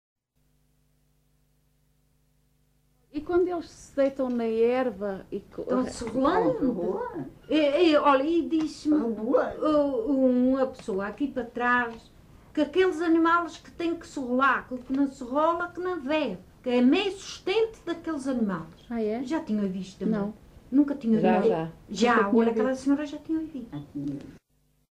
LocalidadeFajãzinha (Lajes das Flores, Horta)